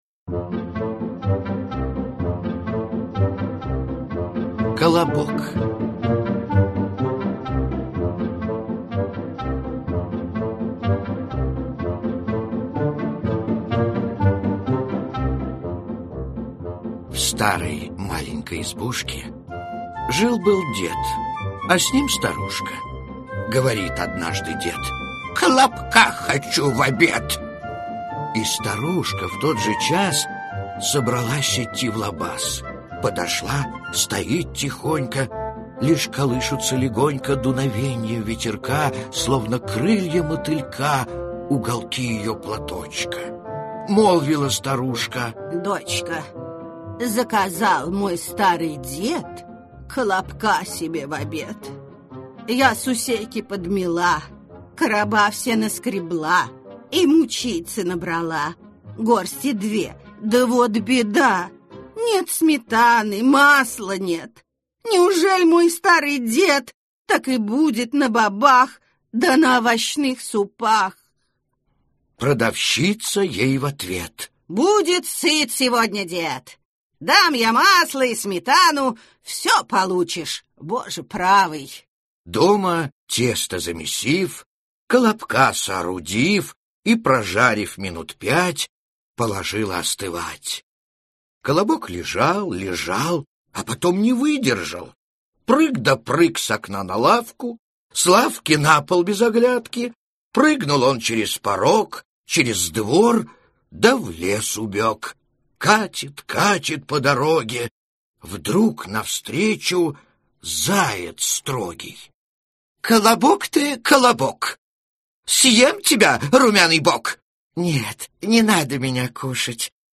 Аудиокнига Старые добрые сказки в стихах Диск 1 | Библиотека аудиокниг